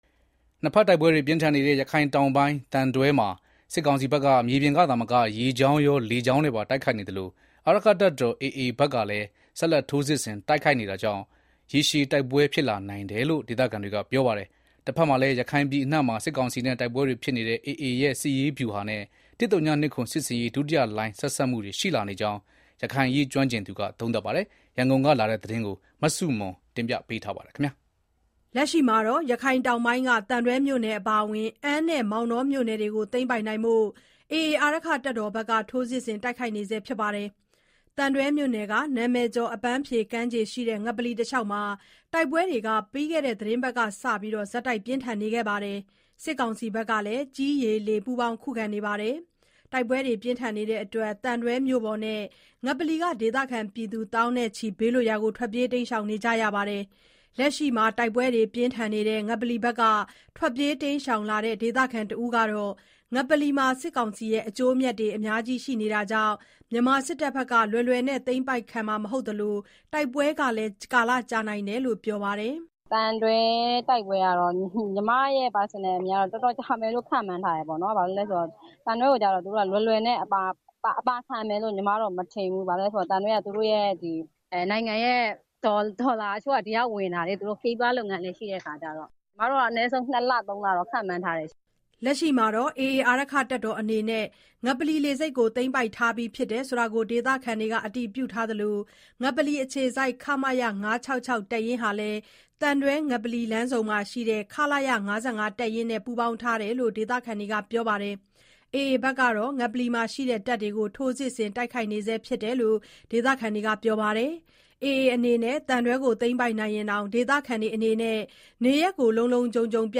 နှစ်ဖက်တိုက်ပွဲတွေပြင်းထန်နေတဲ့ ရခိုင်တောင်ပိုင်း သံတွဲမှာ စစ်ကောင်စီဘက်က မြေပြင်ကသာမက ရေကြောင်းရော လေကြောင်းနဲ့ပါ တိုက်ခိုက်နေသလို အာရက္ခတပ်တော် AA ဘက်ကလည်း ဆက်လက် ထိုးစစ်ဆင် တိုက်နေပါတယ်။ တဖက်မှာလည်း ရခိုင်ပြည်အနှံ့မှာ စစ်ကောင်စီနဲ့ တိုက်ပွဲတွေ ဖြစ်နေတဲ့ AA ရဲ့ စစ်ရေးဗျူဟာနဲ့ ၁၀၂၇ စစ်ဆင်ရေး ဒုတိယလှိုင်း ဆက်စပ်မှုတွေရှိလာနေကြောင်း ရခိုင်အရေးကျွမ်းကျင်သူက သုံးသပ်ပါတယ်။ ရန်ကုန်ကပေးပို့တဲ့သတင်းကို တင်ပြထားပါတယ်။
လက်ရှိမှာတော့ AA အနေနဲ့ ငပလီလေဆိပ်ကို သိမ်းပိုက်ထားပြီးဖြစ်တယ်လို့ ဒေသခံတွေက အတည်ပြုထားသလို ငပလီအခြေစိုက် ခမရ (၅၆၆) တပ်ရင်းဟာလည်း သံတွဲ၊ ငပလီ လမ်းဆုံမှာရှိတဲ့ ခလရ ၅၅ တပ်ရင်းနဲ့ ပူးပေါင်းထားတယ်လို့ ဒေသခံတွေကပြောပါတယ်။ AA ဘက်ကတော့ ငပလီမှာရှိတဲ့ တပ်တွေကို ထိုးစစ်ဆင် တိုက်ခိုက်နေဆဲဖြစ်တယ်လို့ ဒေသခံတွေကပြောပါတယ်။ AA အနေနဲ့ သံတွဲကို သိမ်းပိုက်နိုင်ရင်တောင် ဒေသခံတွေအနေနဲ့ နေရပ်ကို လုံလုံခြုံခြုံ ပြန်နိုင်မှာ မဟုတ်သေးဘူးလို့လည်း ငပလီဒေသခံ အမျိုးသမီးတဦးကပြောပါတယ်။